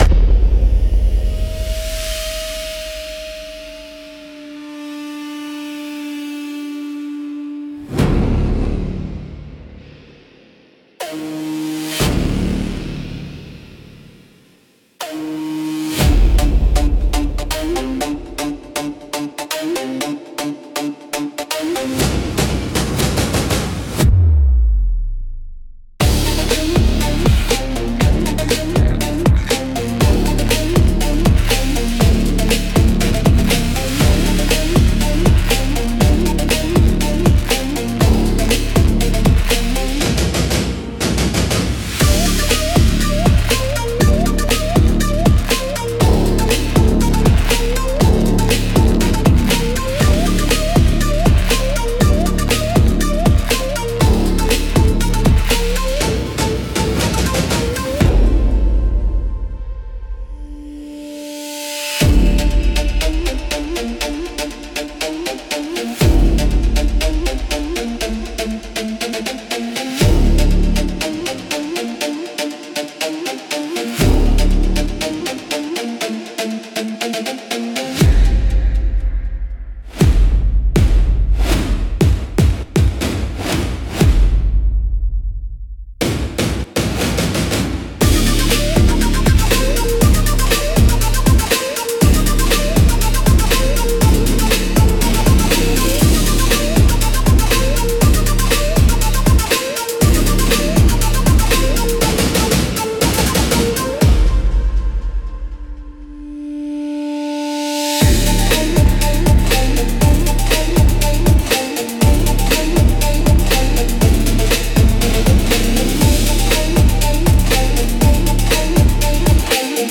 BGM用途としては、サスペンスや戦闘、追跡劇など緊迫したシーンに最適です。
危機感や焦燥感を強調したい場面にぴったりのジャンルです。